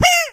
Media:mrp_minip_atk_02.ogg Mr. P laughs
P先生的笑声